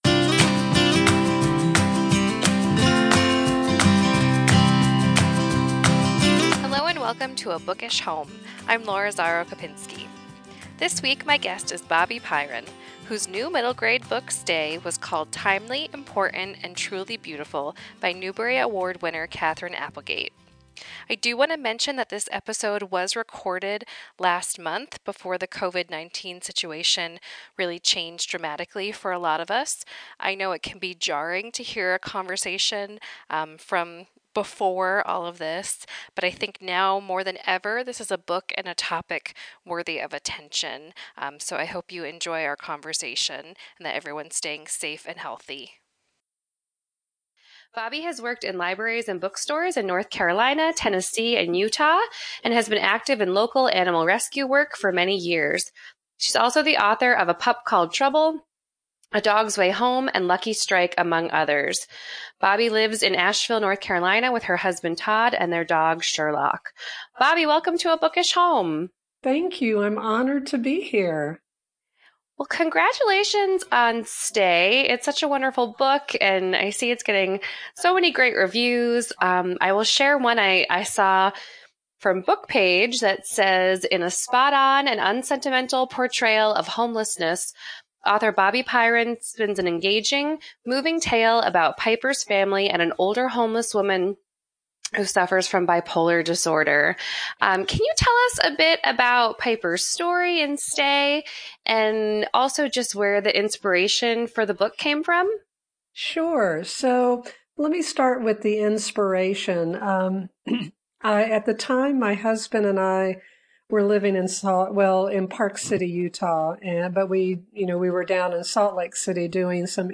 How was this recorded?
I know it can be jarring to hear a conversation from “Before”.